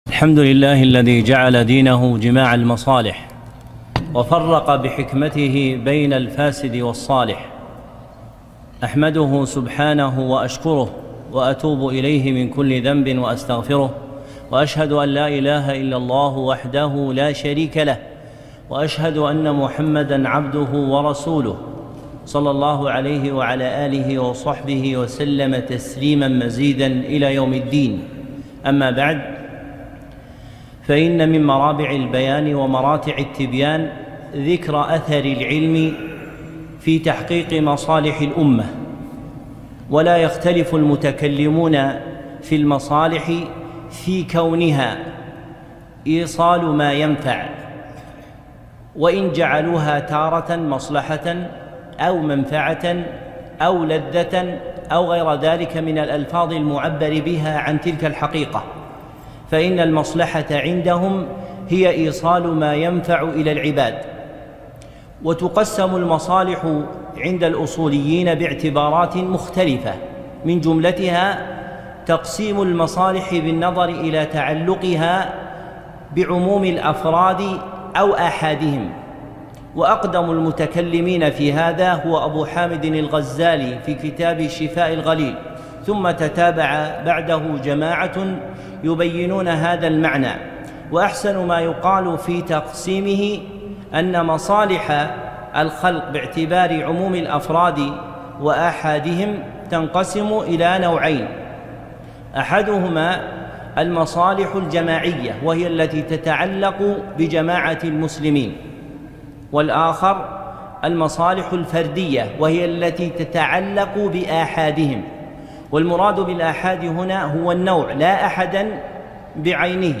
محاضرة - مصالح الأمة، وأثر العلم الشرعي في تحقيقها